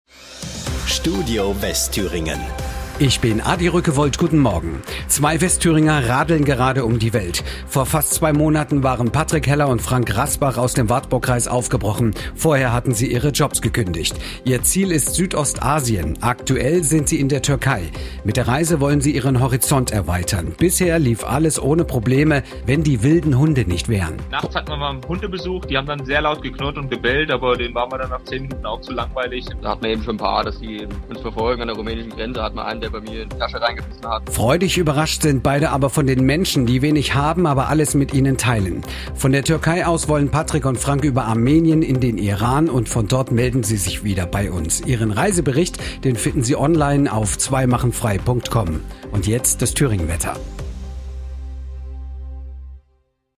Unser Zusammenschnitt  lief bereits mehrmals in den Nachrichten im Radio.